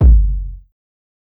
KICK_QUEENS.wav